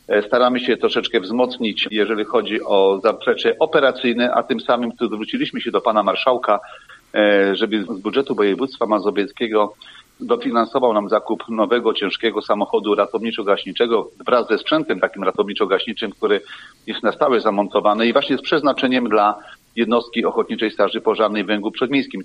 O złożonym wniosku mówi Wójt Gminy, Stefan Prusik: